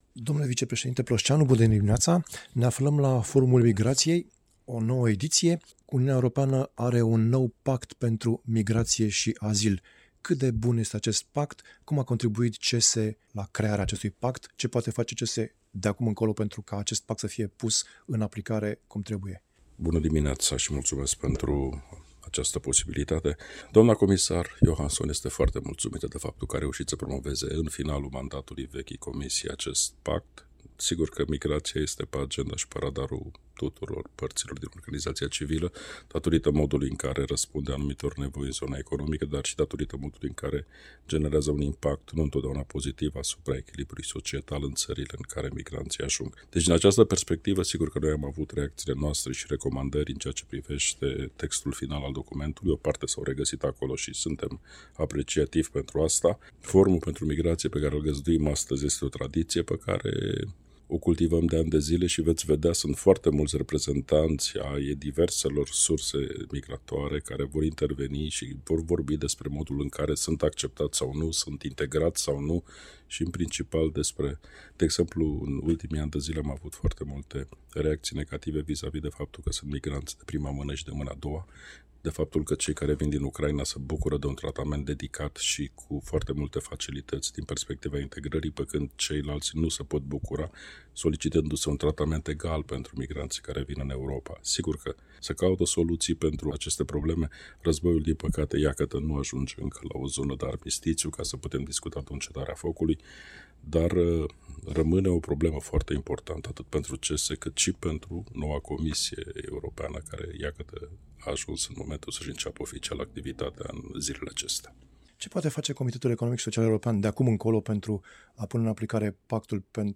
Interviu cu vicepreşedintele CESE Laurenţiu Plosceanu